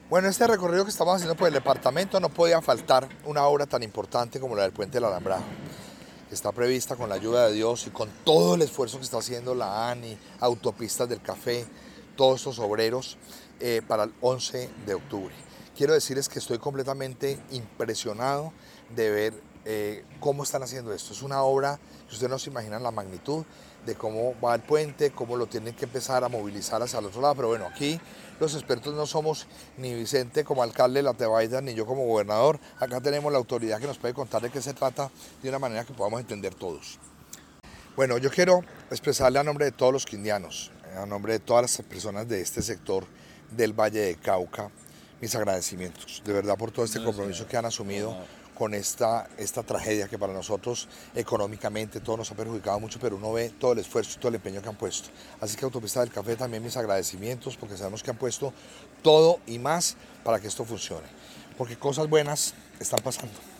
Audio de Roberto Jairo Jaramillo Cárdenas, Gobernador del Quindío:
gobernador-del-Quindio-Roberto-Jairo-Jaramillo.mp3